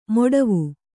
♪ moḍavu